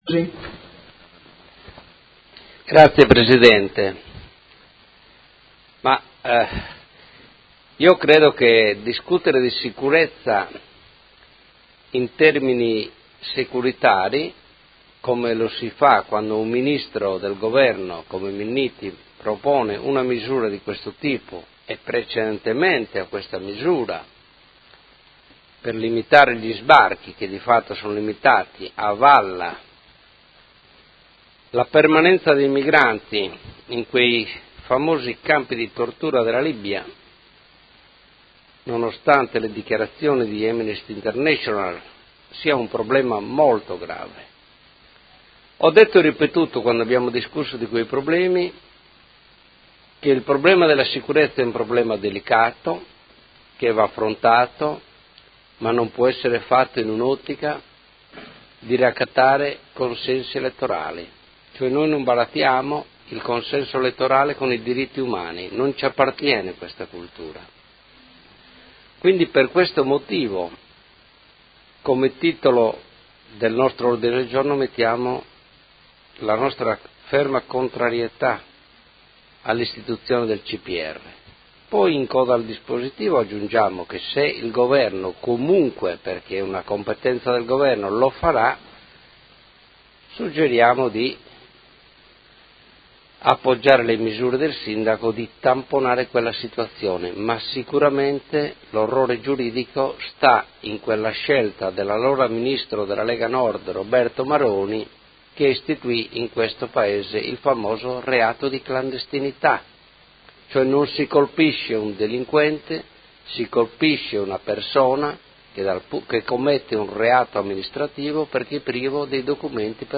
Seduta del 12/04/2018 Dibattito. Ordine del giorno 54394, Emendamento 54490 e ordine del giorno 54480 sulla sicurezza